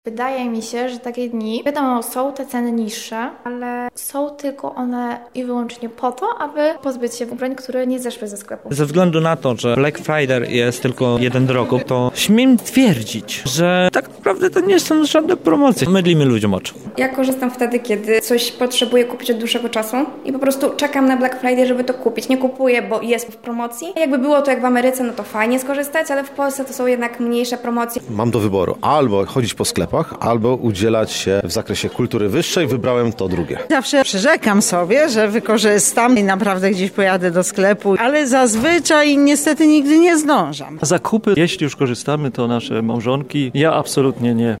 Zapytaliśmy mieszkańców Lublina, co sądzą na temat dzisiejszego święta:
sonda